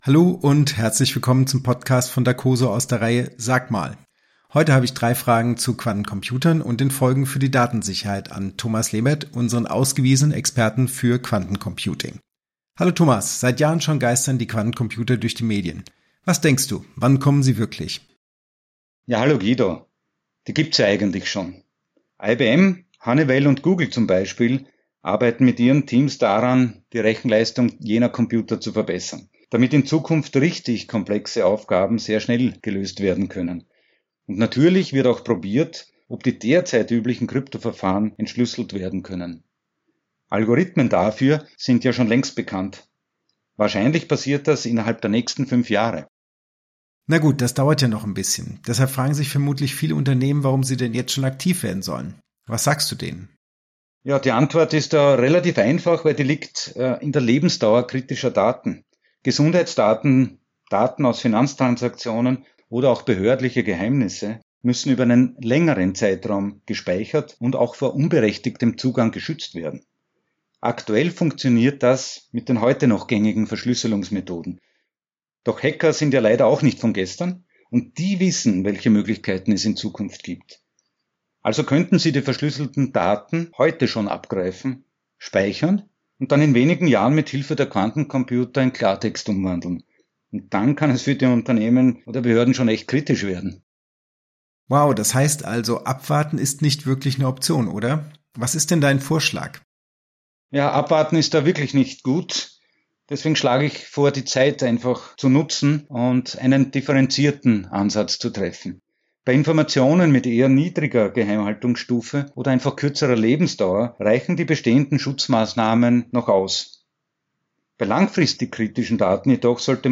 Was können Unternehmen jetzt schon tun? Ein komplexes Feld, aber mit Wiener Schmäh klingt’s gleich viel charmanter.